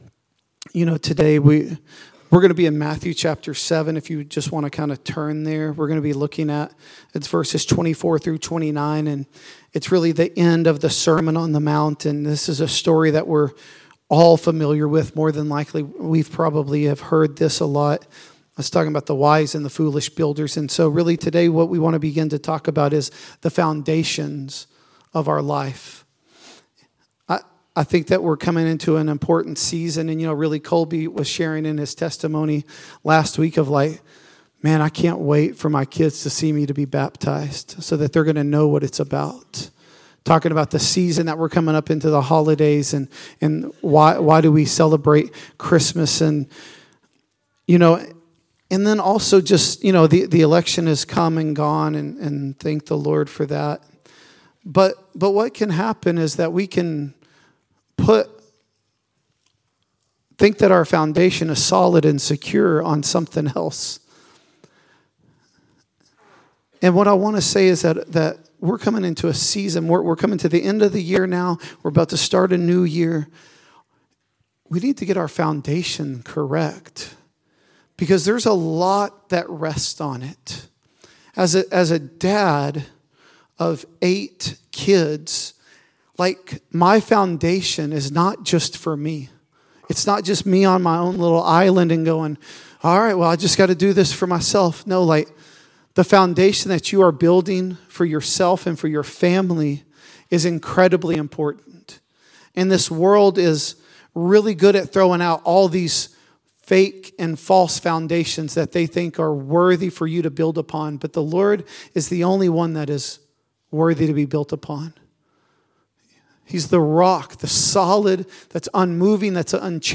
The Gathering at Adell Audio Sermons Building a Solid Foundation Play Episode Pause Episode Mute/Unmute Episode Rewind 10 Seconds 1x Fast Forward 30 seconds 00:00 / 52:48 Subscribe Share RSS Feed Share Link Embed